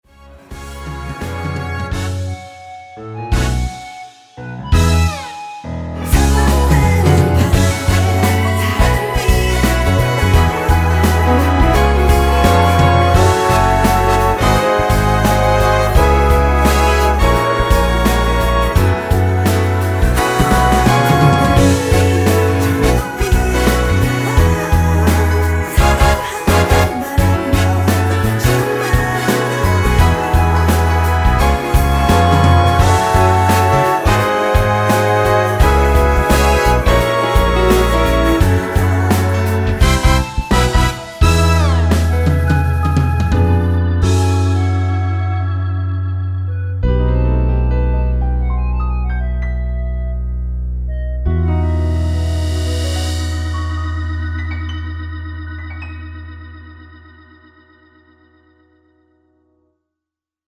2분56초 부터 10초 정도 보컬 더블링 된 부분은 없습니다.(미리듣기 확인)
원키에서(+2)올린 멜로디와 코러스 포함된 MR입니다.
Eb
앞부분30초, 뒷부분30초씩 편집해서 올려 드리고 있습니다.
(멜로디 MR)은 가이드 멜로디가 포함된 MR 입니다.